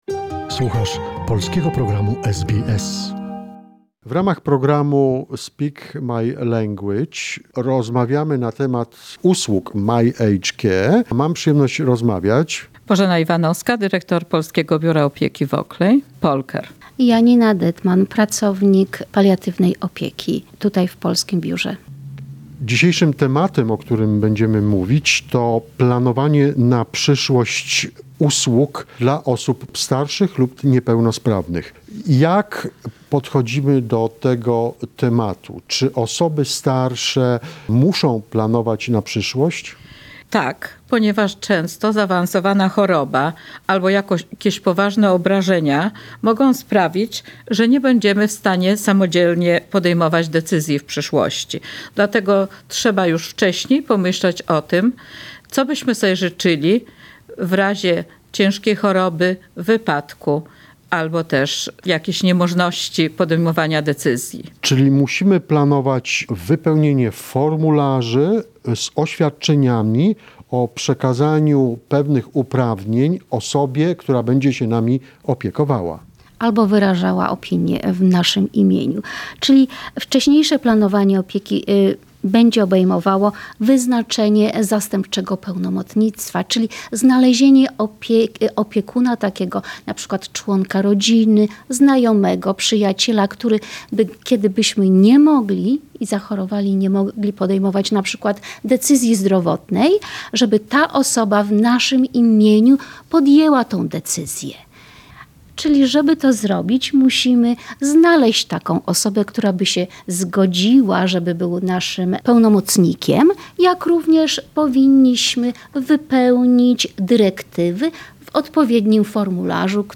This is another conversation in the "Speak My Language" series about helping the elderly in Australia.